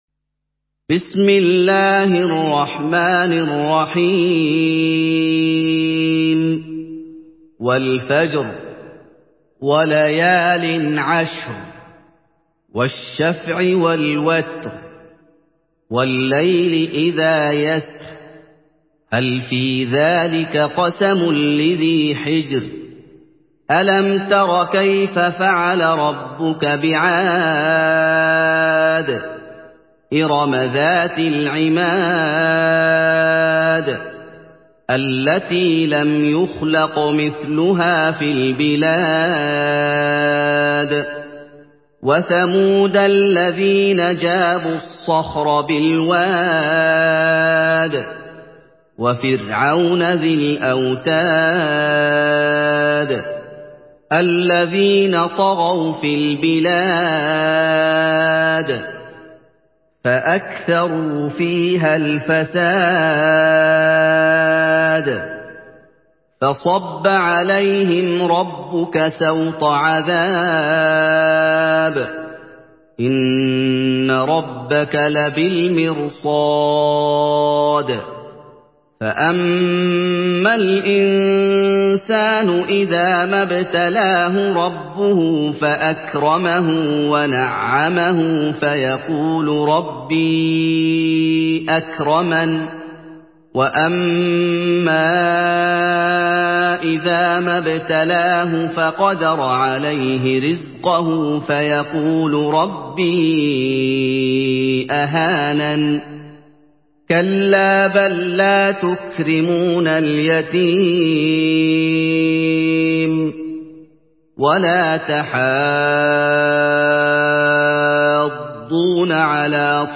مرتل
تلاوت